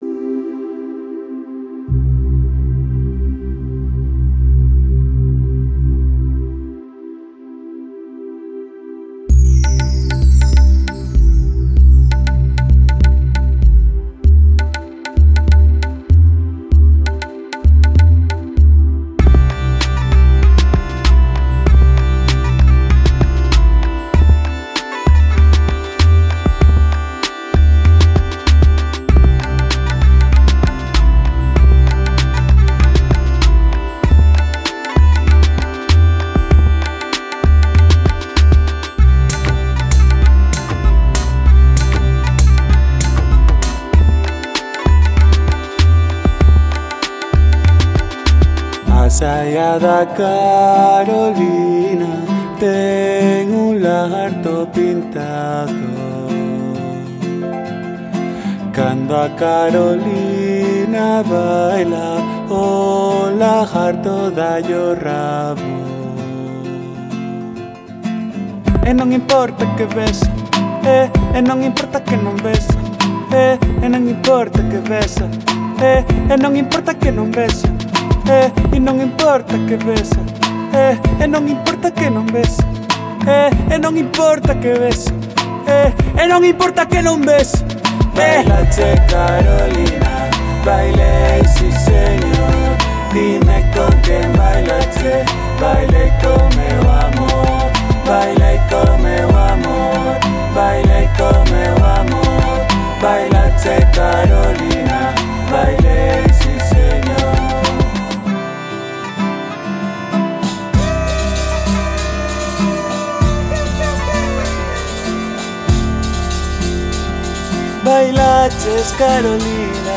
Reimagining Galician Folk: Exploring Contemporary Pop Fusions through Electronic Soundscapes
Väitöskirja syventyy tuotannon yksityiskohtiin, selittäen kuinka elektroniset äänet, kokeelliset tekniikat ja studiotyökalut yhdistettiin ikonisten galicialaisten instrumenttien, kuten tamburiinin, säkkipillin ja klassisen kitaran, kanssa.